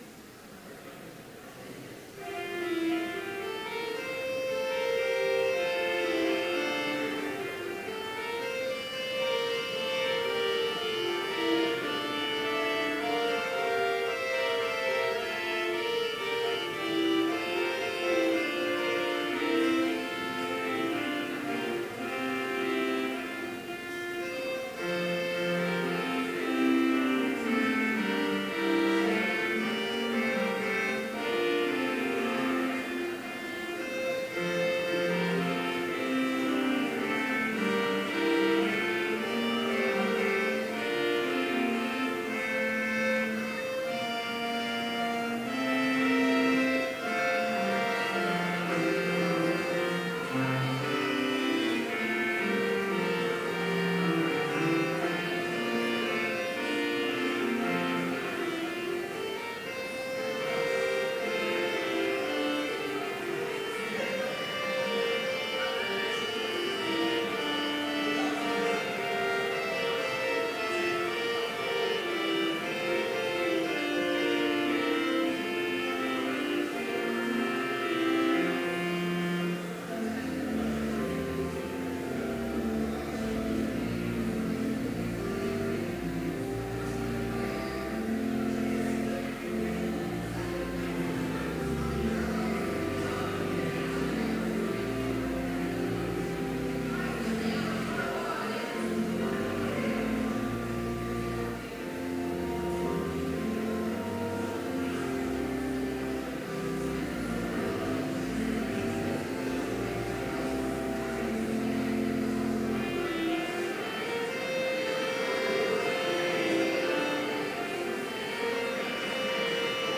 Complete service audio for Chapel - September 10, 2015